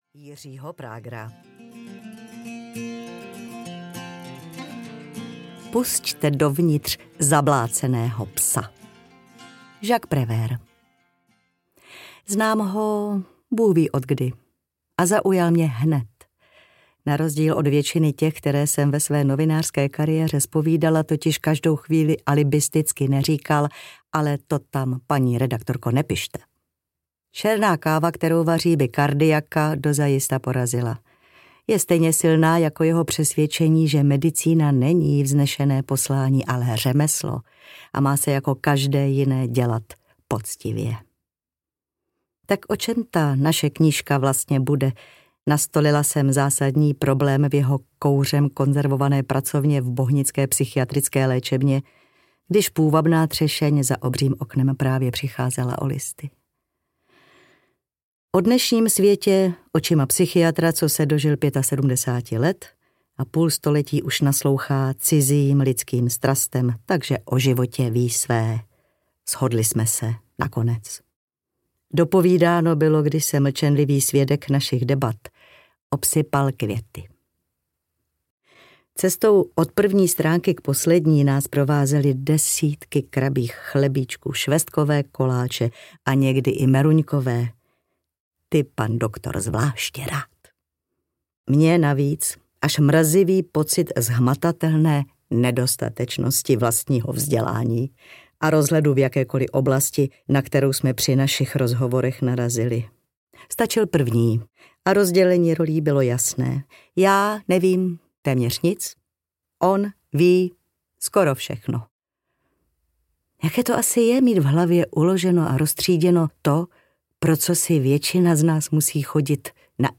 Všichni žijem v blázinci audiokniha
Ukázka z knihy
• InterpretJiří Prager, Milena Steinmasslová